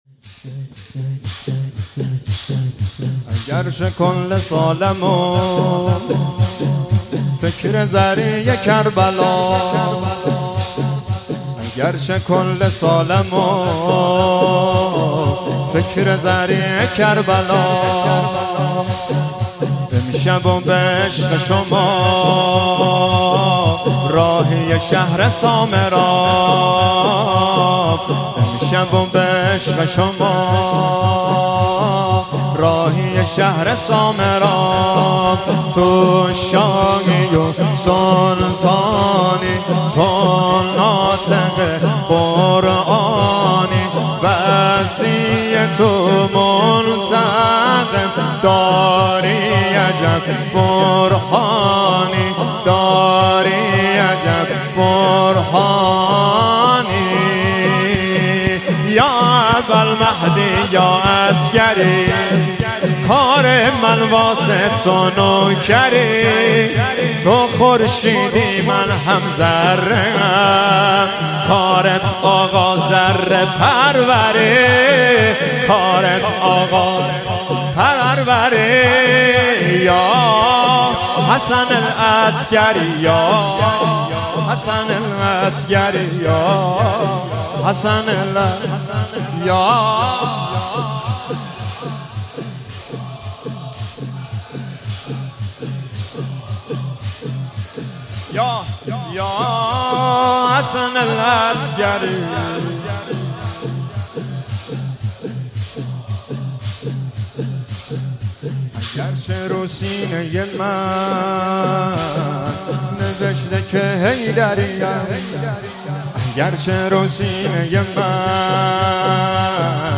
در بیرق معظم علقمه کرج اجرا شده است